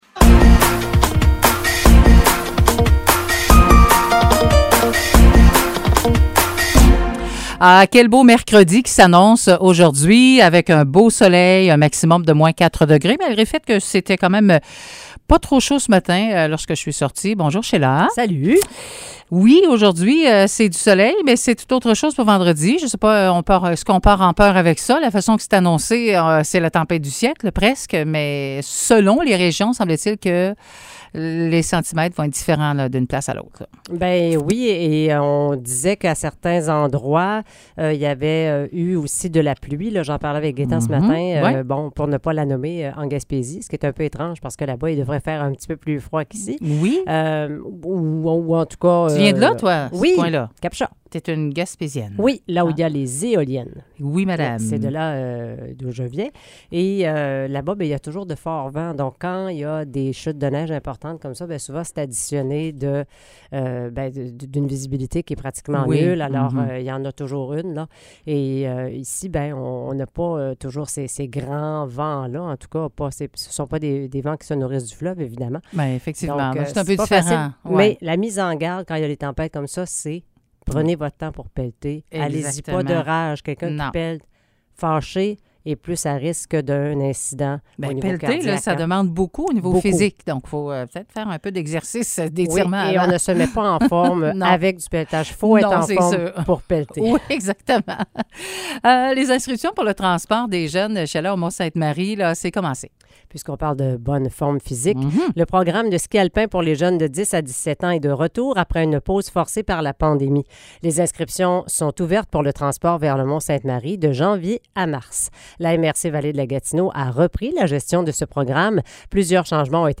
Nouvelles locales - 14 décembre 2022 - 9 h